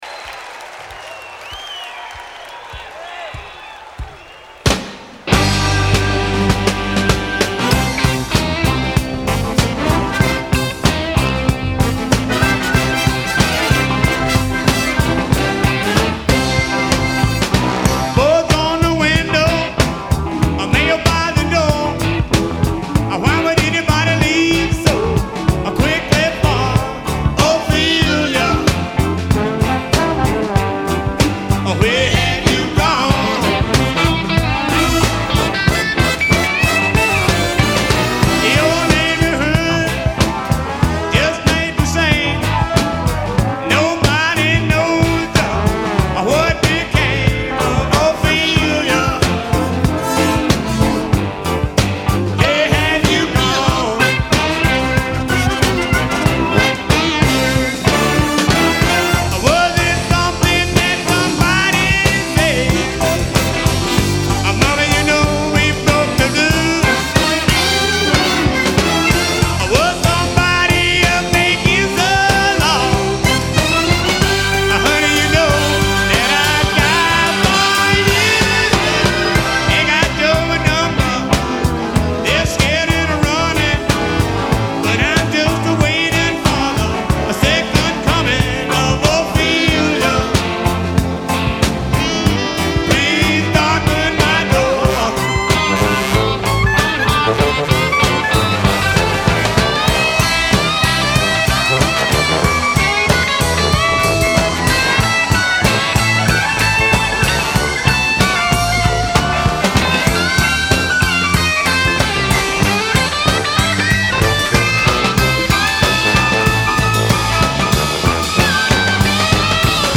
concert version